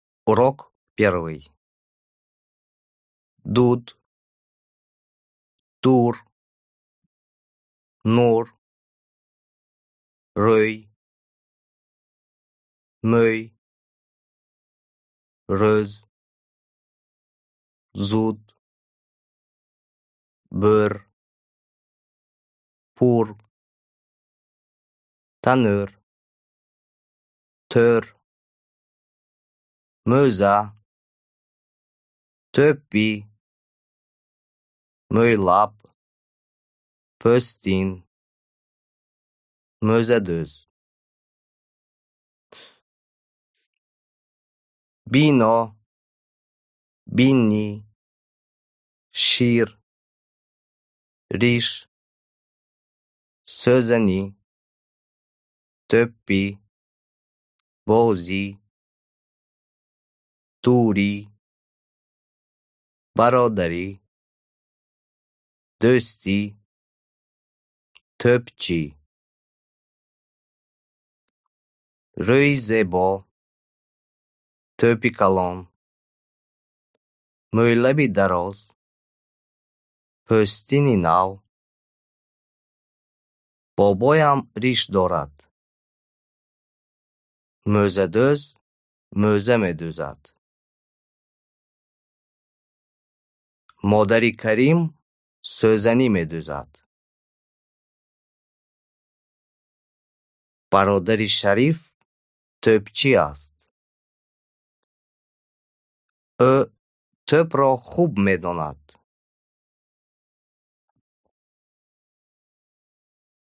Аудиокнига Самоучитель таджикского языка. МР3 | Библиотека аудиокниг